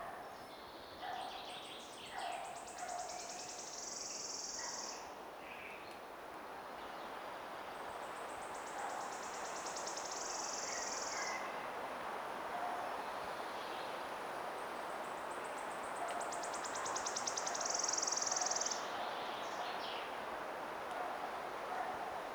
8. Eastern Phoebe (Sayornis phoebe)
Sound: Raspy “fee-bee, fee-bee.”